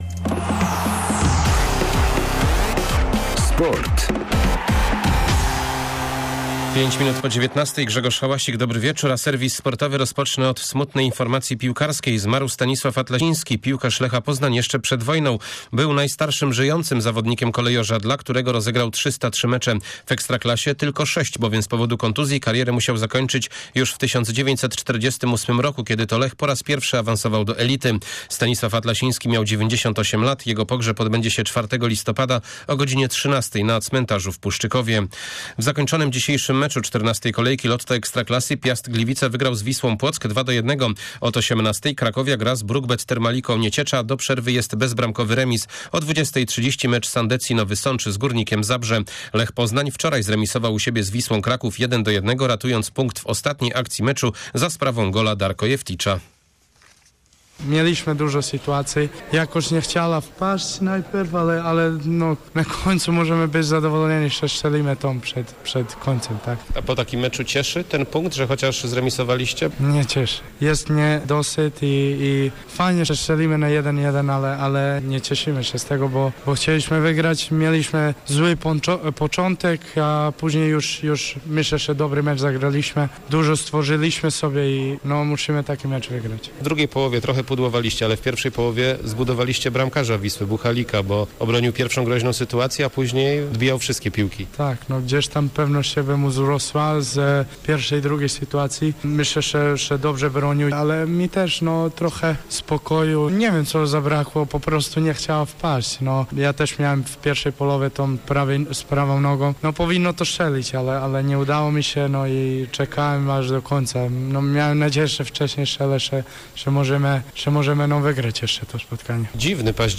28.10 Serwis sportowy g. 19.05